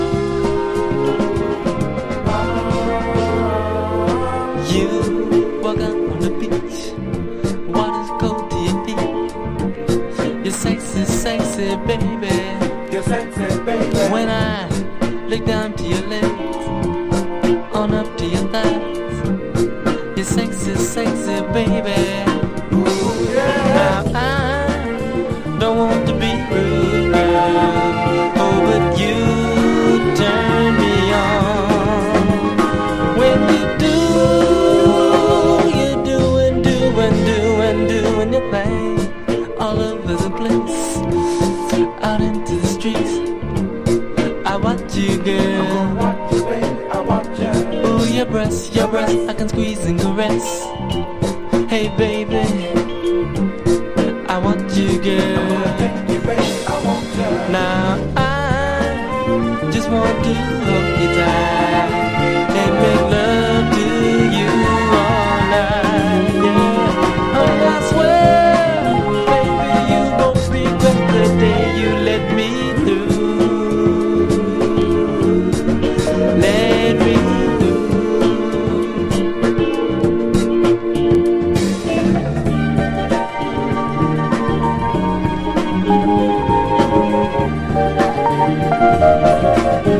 大所帯ファンクバンドの1975年1ST。